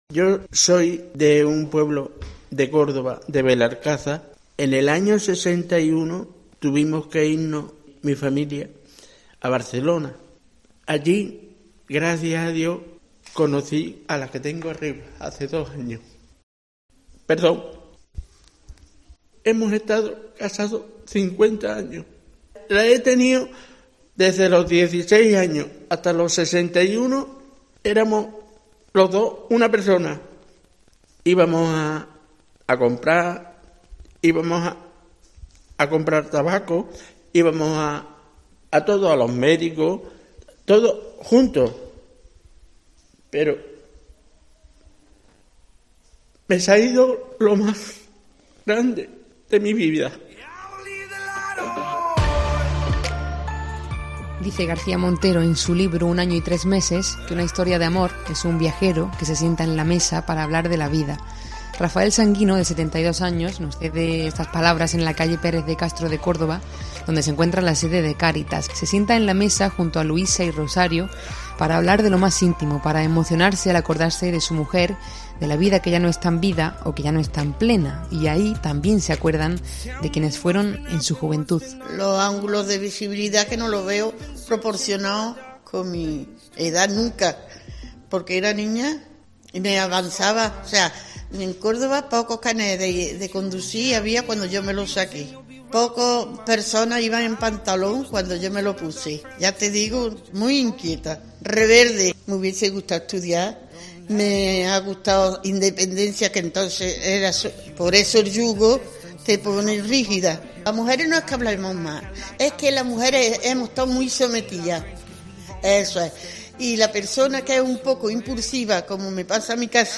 COPE se acerca hasta ellos para para ponerle voz a ese paso del tiempo, donde, a veces, la soledad se hace un hueco demasiado profundo.